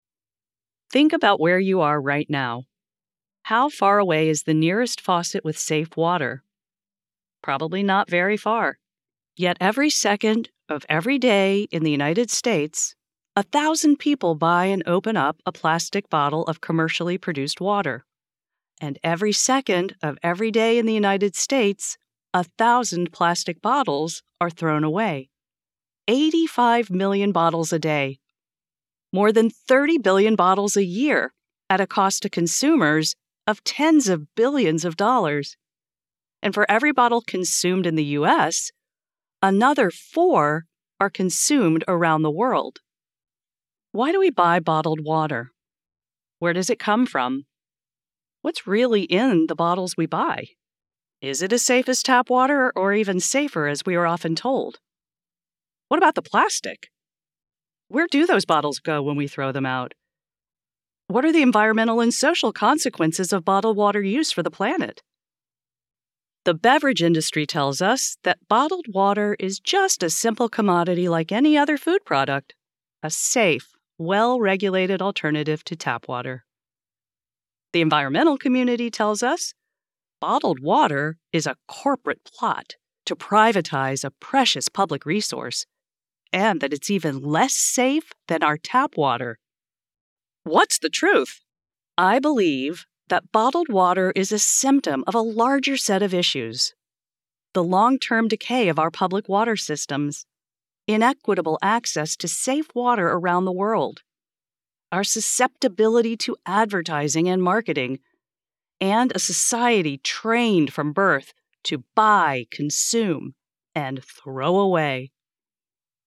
Non-Fiction | Environmentalism | Perspectives on bottled water industry
Professional Home Studio
- Professional Sound Treatment